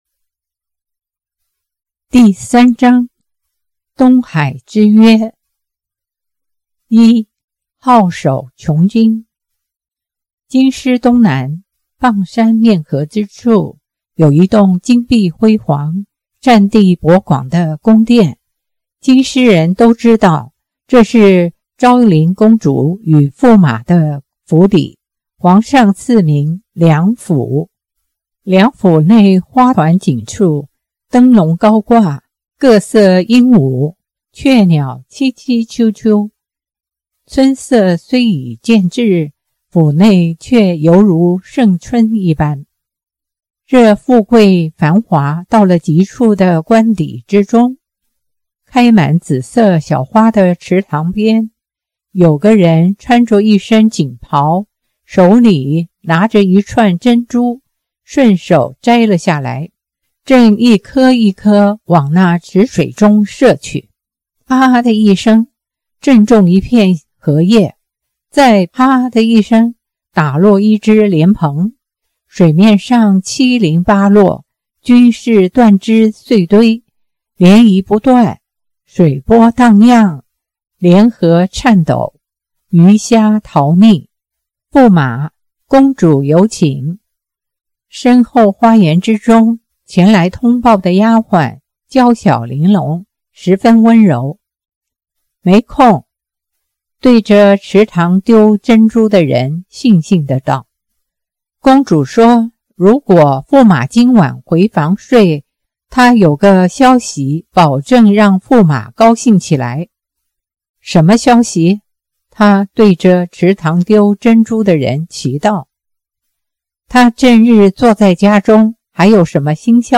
好讀新有聲書